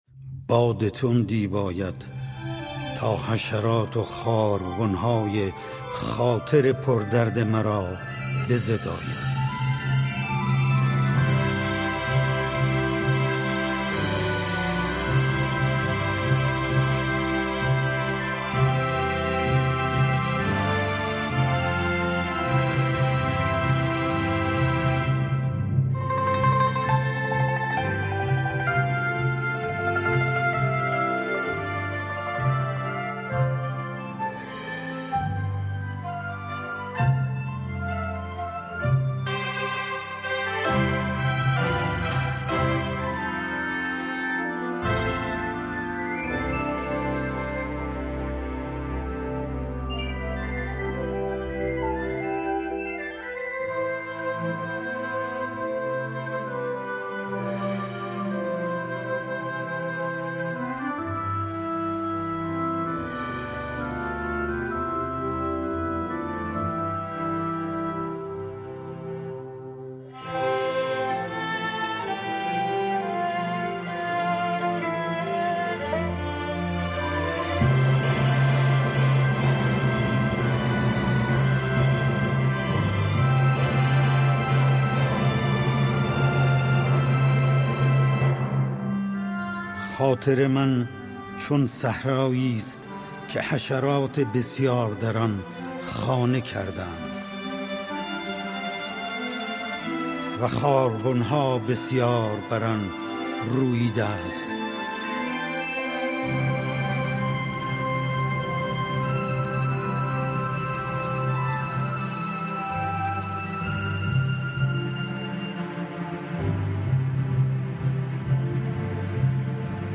(نوازنده کمانچه)
(نوازنده سنتور)
اثری در سه موومان برای ساز‌های زهی